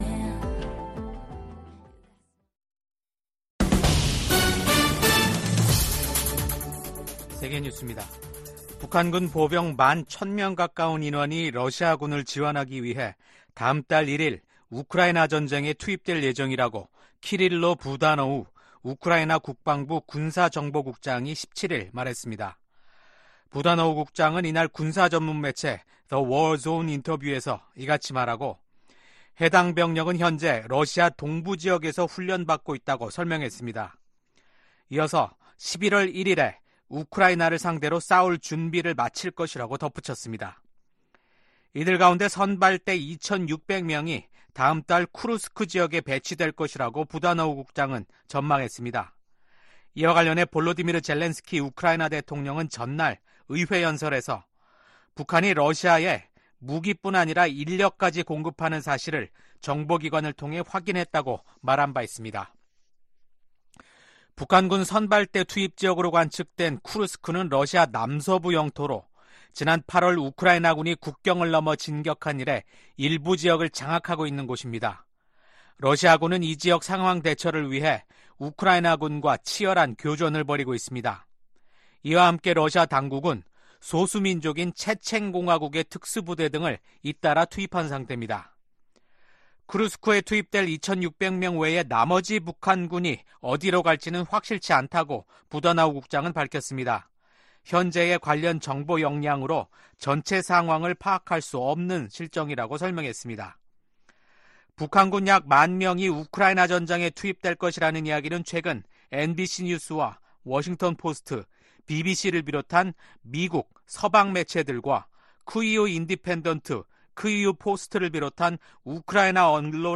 VOA 한국어 아침 뉴스 프로그램 '워싱턴 뉴스 광장' 2024년 10월 19일 방송입니다. 북한이 한국을 헌법상 적대국으로 규정한 가운데 김정은 국무위원장은 전방부대를 방문해 한국을 위협하는 행보를 보였습니다. 윤석열 한국 대통령은 국가안보실, 국방부, 국가정보원 핵심 관계자 등이 참석한 가운데 ‘북한 전투병의 러시아 파병에 따른 긴급 안보회의’를 열어 대응 방안을 논의했다고 대통령실이 전했습니다.